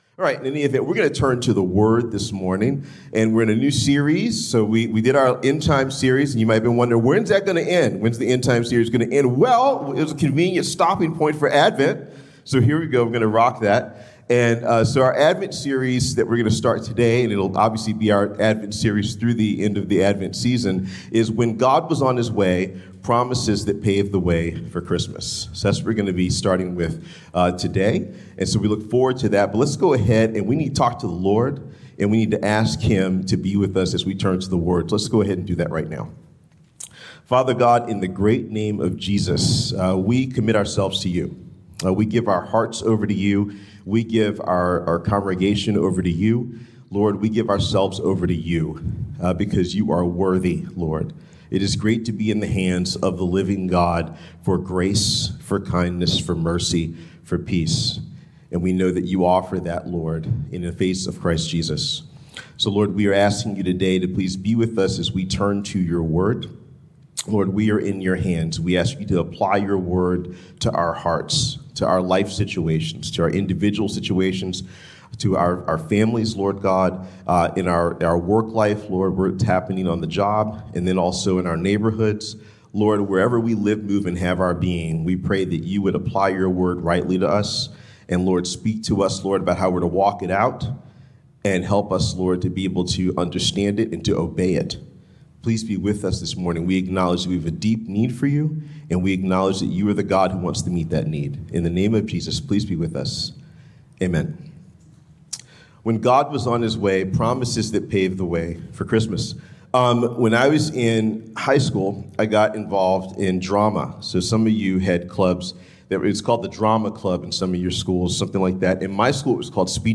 Sermons | Journey Community Church